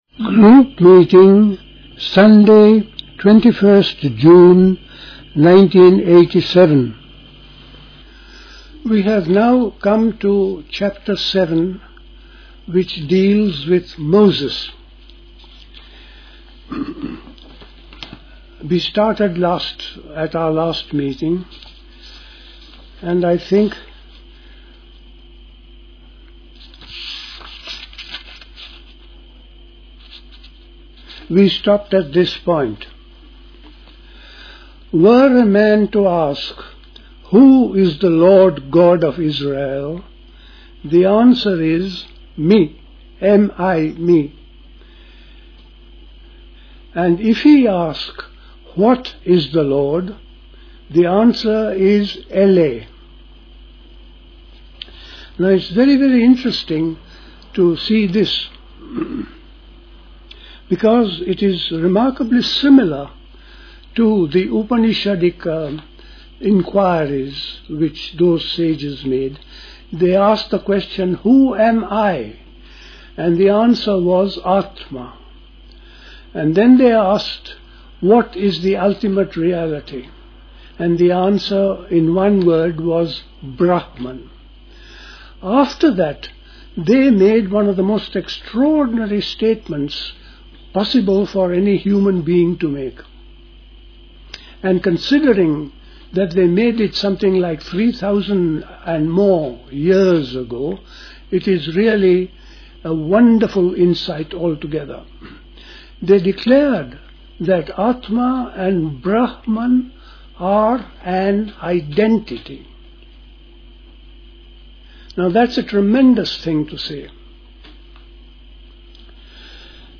A talk
at Dilkusha, Forest Hill, London on 21st June 1987